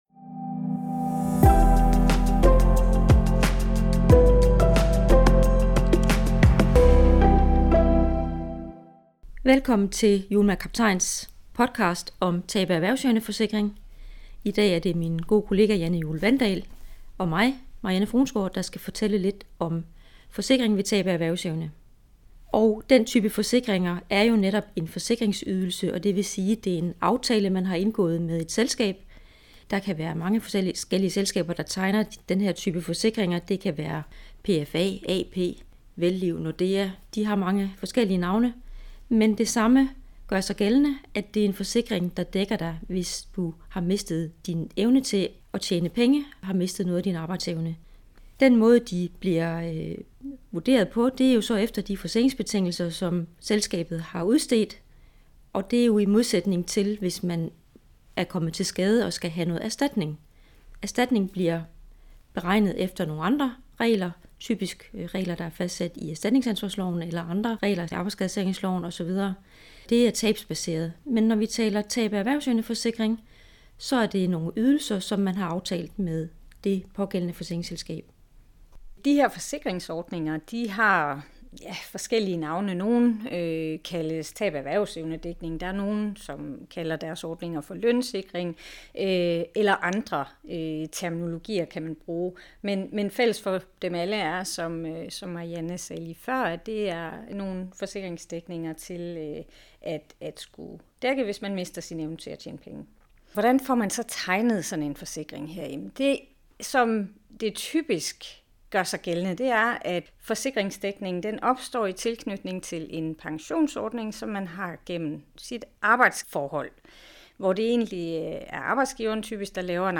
I denne podcast fortæller to af vores erstatningsadvokater om forsikringsdækning ved tab af erhvervsevne.